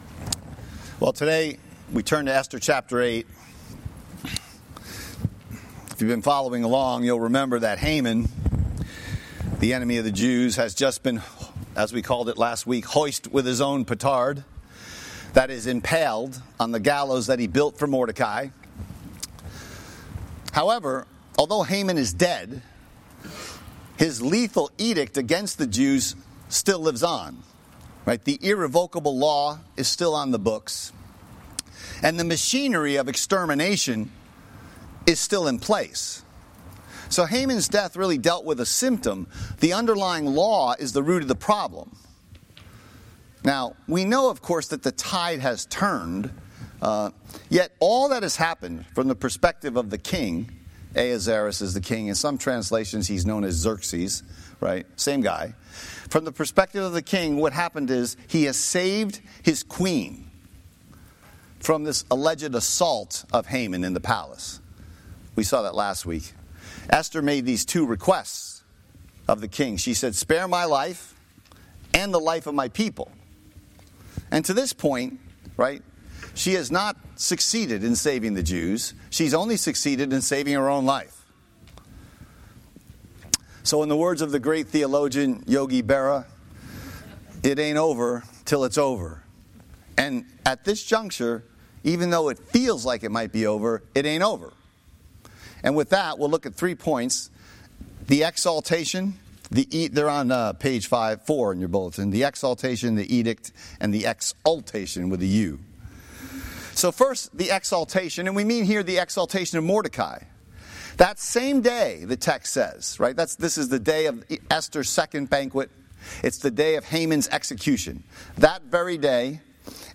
Sermon Text: Esther 8:1-17